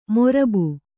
♪ morabu